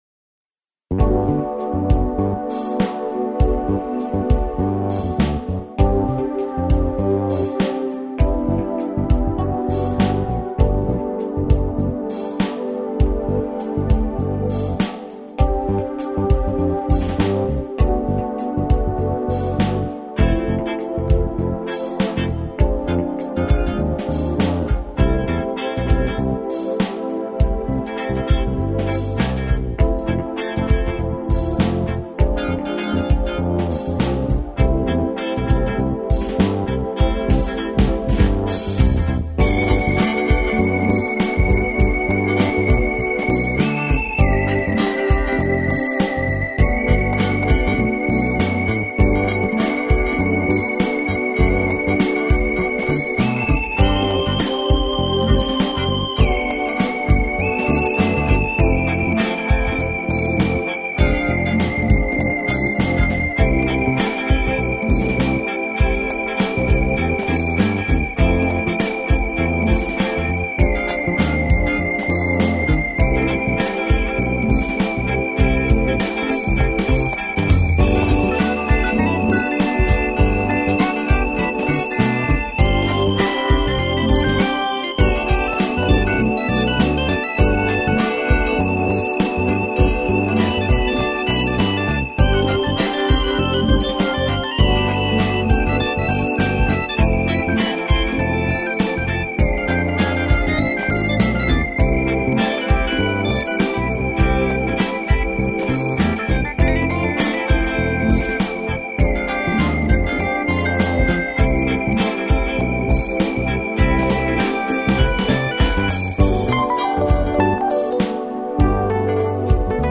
• Жанр: Фанк
инструментальная композиция